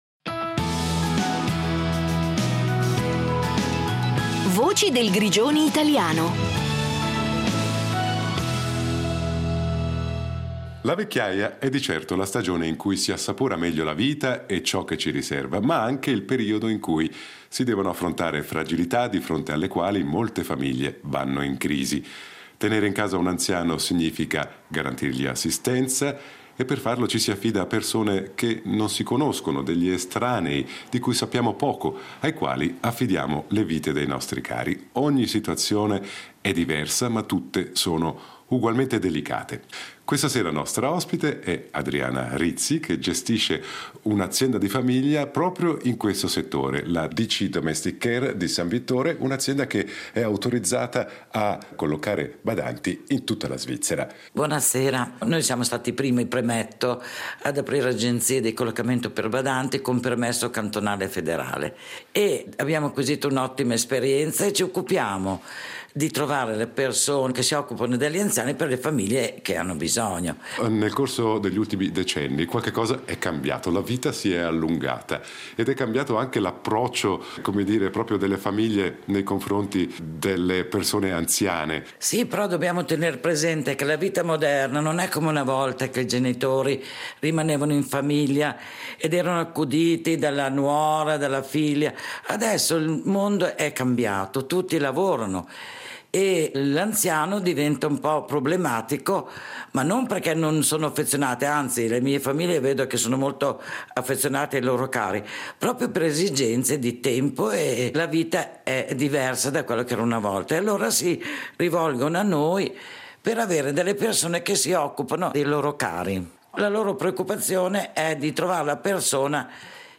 A colloquio